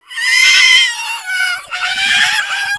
ONFIRE4.WAV